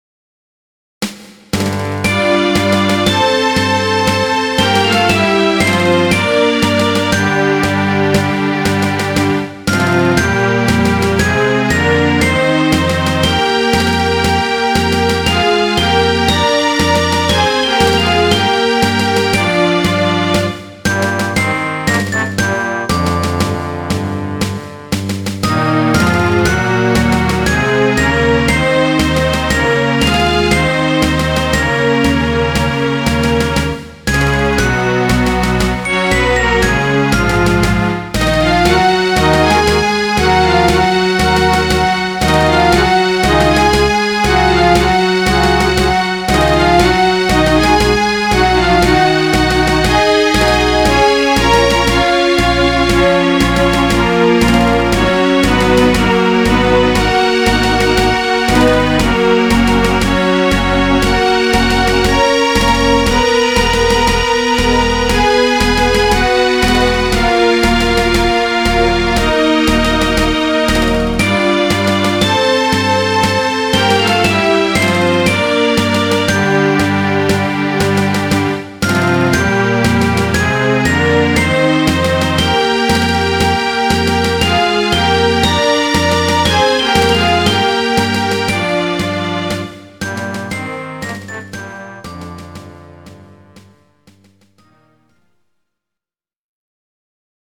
GS音源。